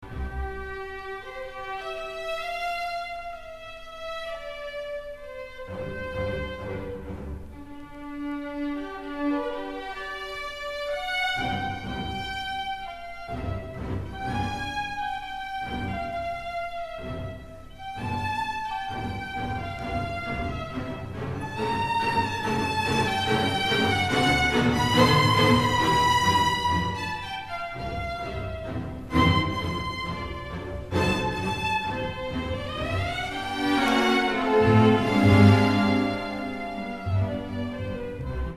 Teema laajenee hitaasti täyttämään sävelikköä, kroomaa.
Fantastisen sinfonian 1. osa, johtoaihe, harjoitusnumeron 5 jälkeen.